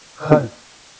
speech
keyword-spotting
speech-commands